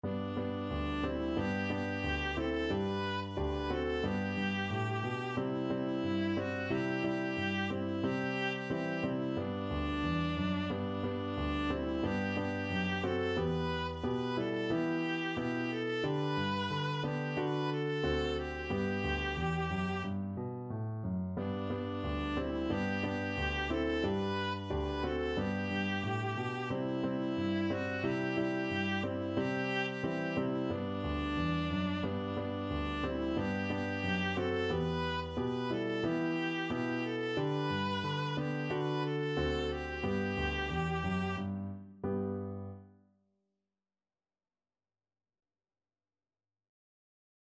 Viola
G major (Sounding Pitch) (View more G major Music for Viola )
Steady two in a bar = c. 90
2/2 (View more 2/2 Music)